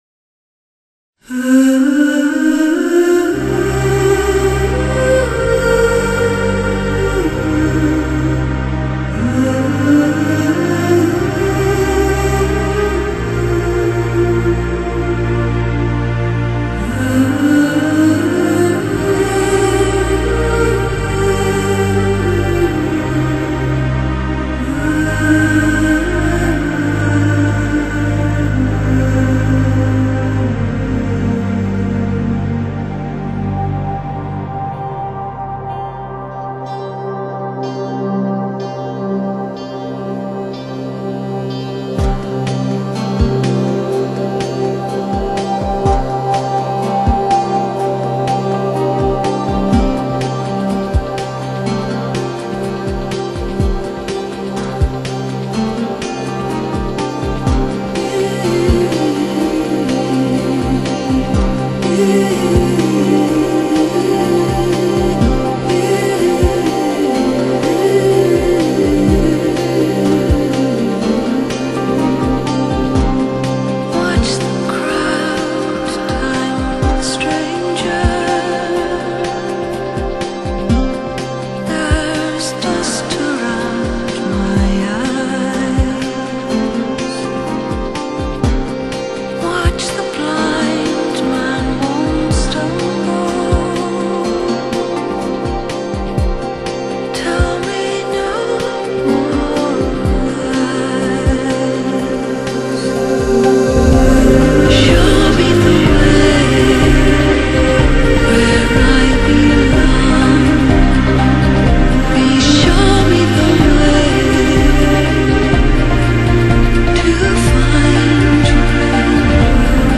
凯尔特人声
她耳语般的音调魅力十足，她音乐的美触摸着你的灵魂。
genre: Celtic Pop